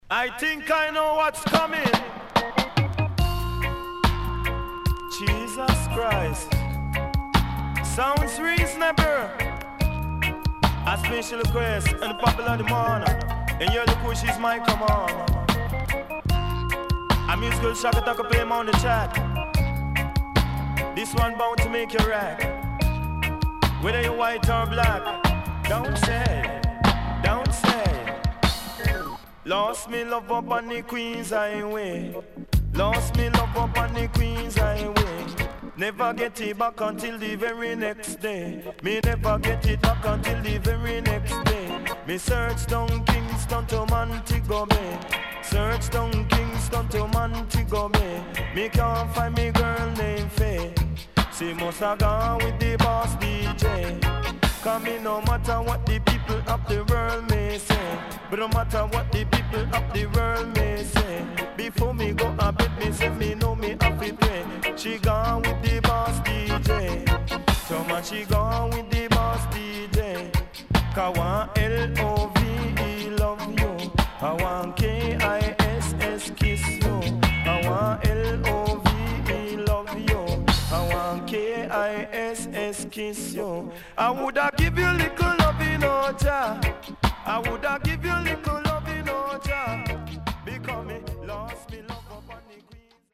HOME > LP [DANCEHALL]  >  EARLY 80’s
SIDE A:所々チリノイズがあり、少しプチノイズ入ります。